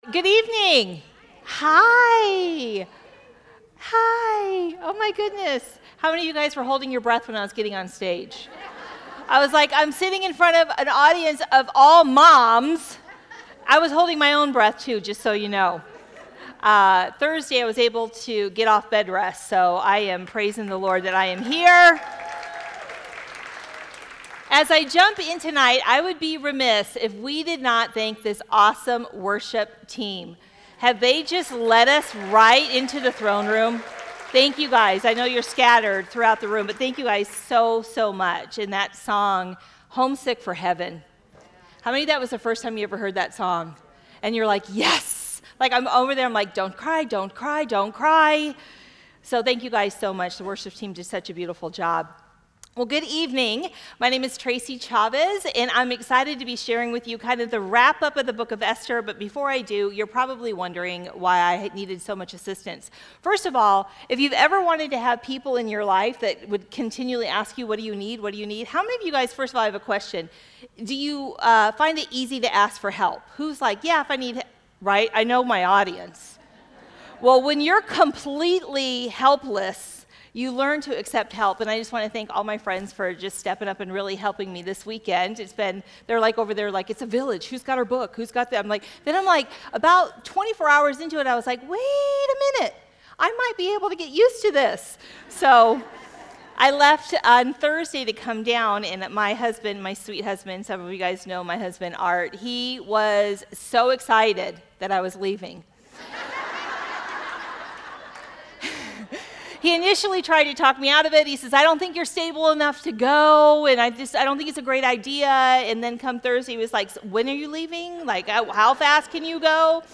Women's Retreat 2025